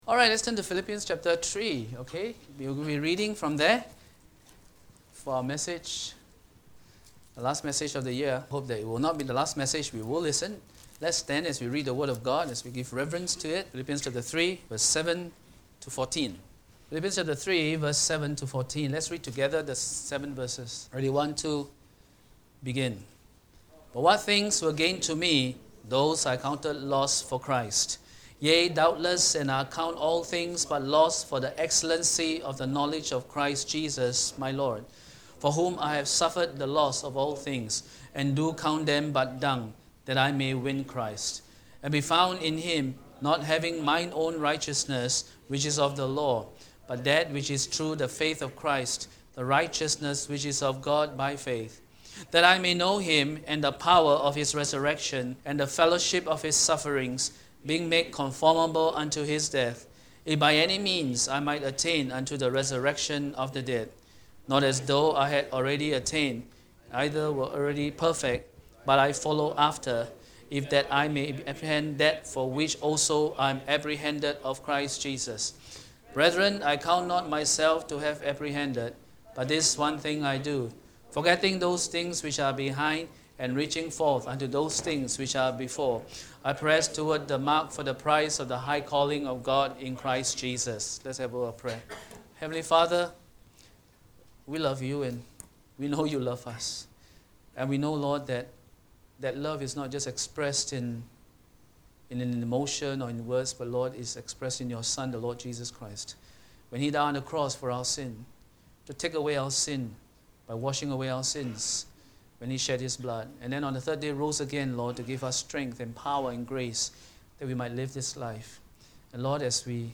Worship Service